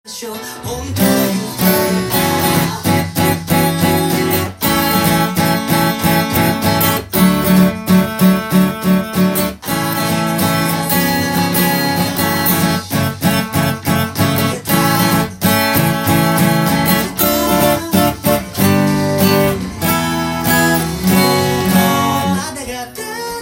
音源に合わせて譜面通り弾いてみました
ミュートしながらスタッカート気味に弾いていきます。